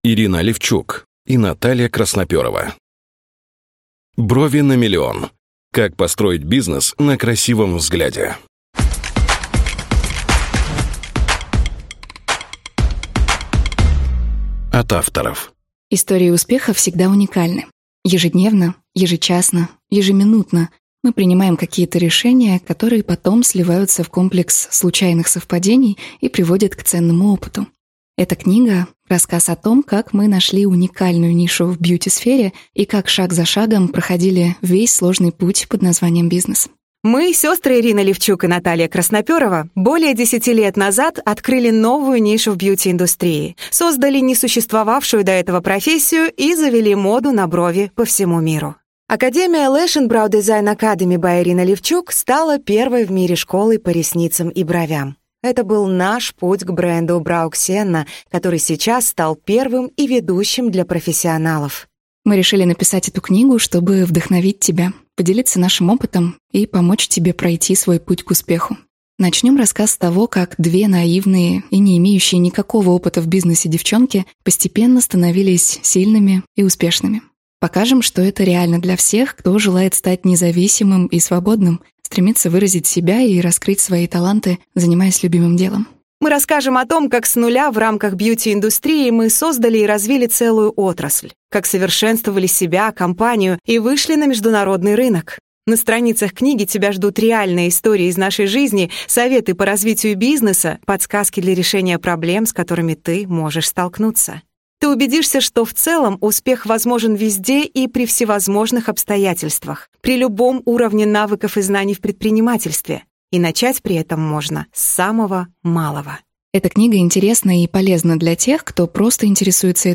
Аудиокнига Брови на миллион. Как построить бизнес на красивом взгляде!?